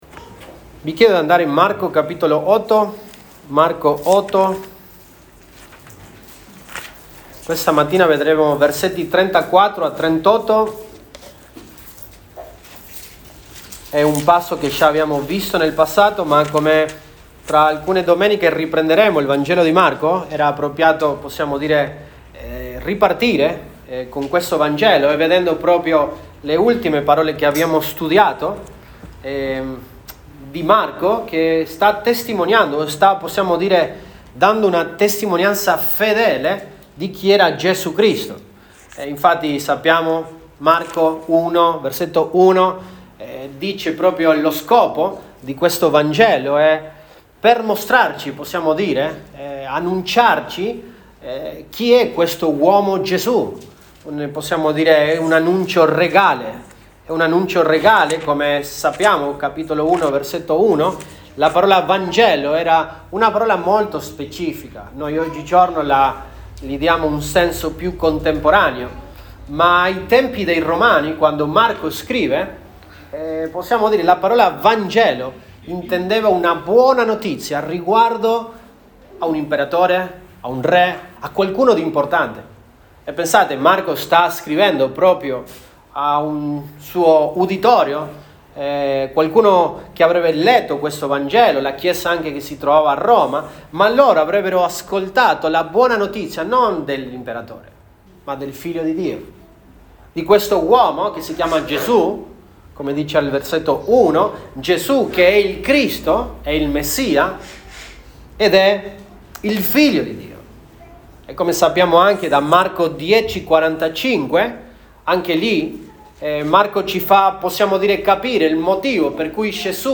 Apr 27, 2025 L’essenza del vero discepolo di Gesù Cristo MP3 Note Sermoni in questa serie L'essenza del vero discepolo di Gesù Cristo.